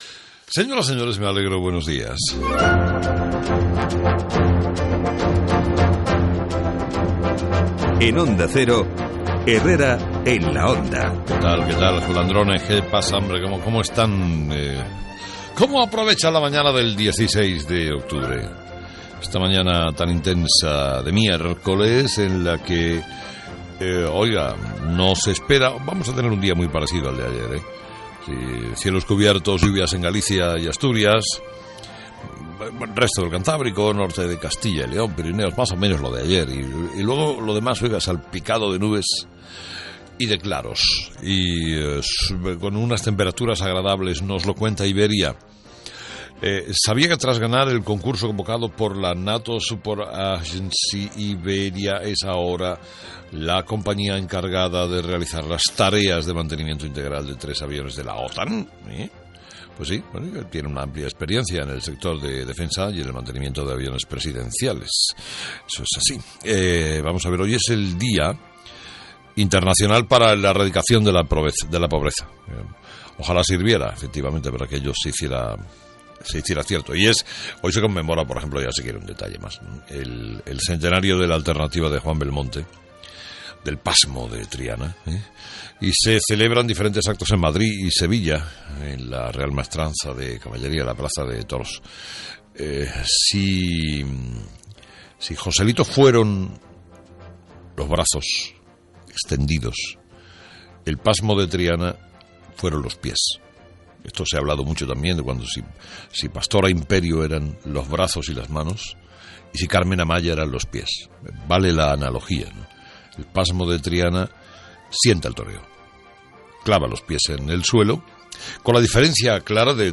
Carlos Herrera se refiere en su editorial al documento de 50 folios que ha elaborado la Generalitat de Cataluña, en el que se recogen los supuestos agravios del Gobierno central hacia Cataluña.